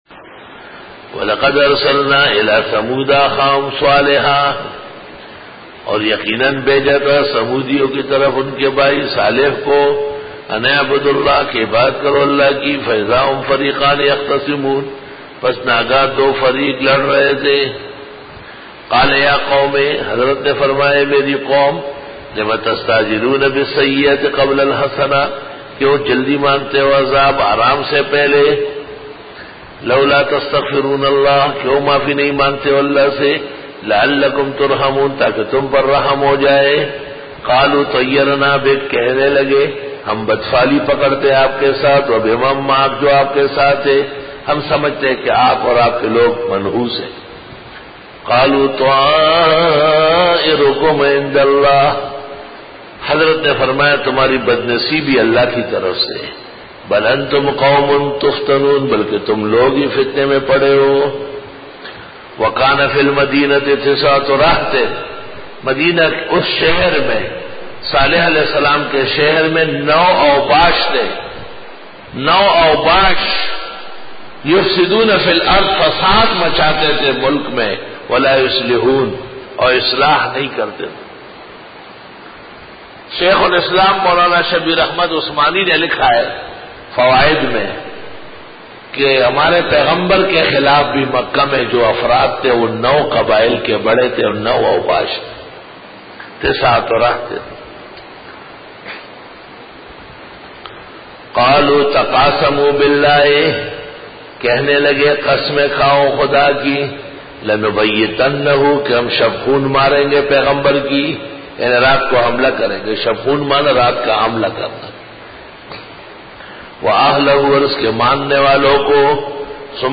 Dora-e-Tafseer 2012